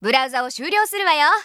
System Voice